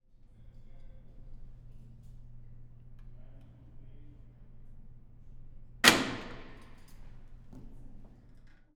Sound file 1.2 Hydraulic unlocking of a door